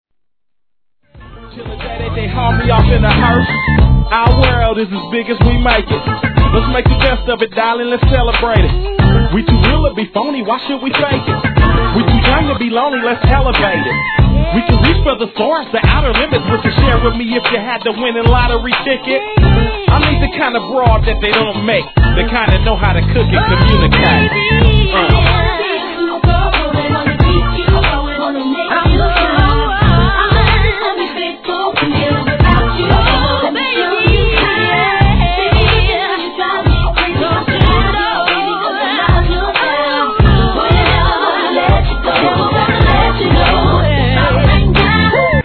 1. HIP HOP/R&B
音質もバッチリです♪